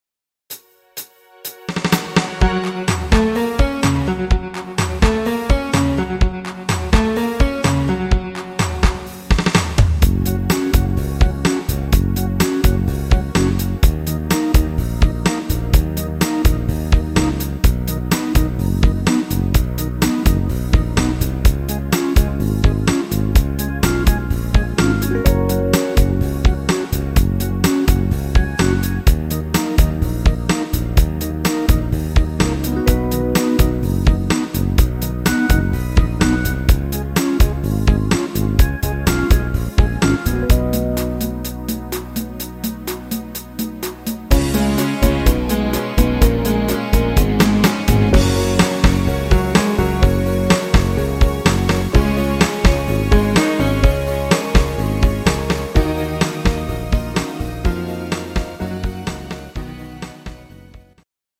Rhythmus  Cha cha
Art  Deutsch, Popschlager, Schlager 2020er